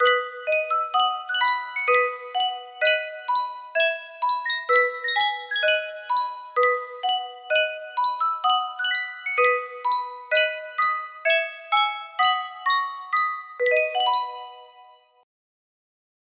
Sankyo Music Box - Battle Hymn of the Republic SU music box melody
Full range 60